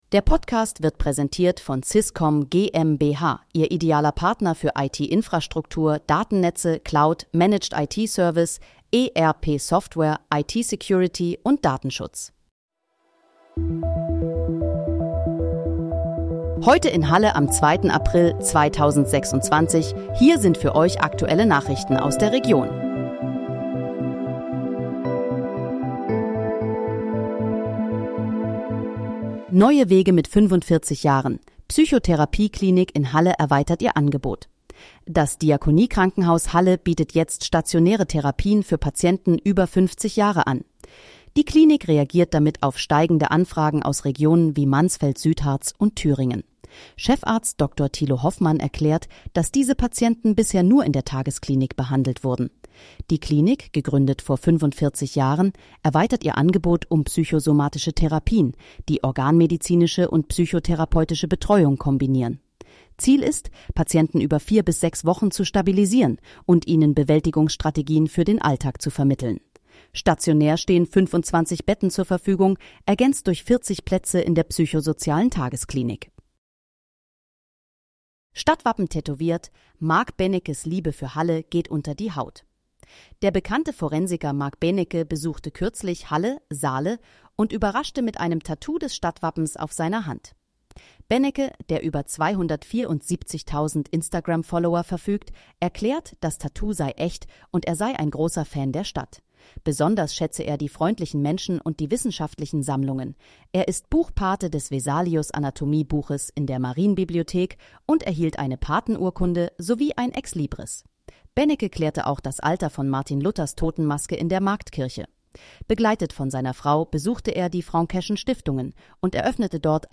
Heute in, Halle: Aktuelle Nachrichten vom 02.04.2026, erstellt mit KI-Unterstützung
Nachrichten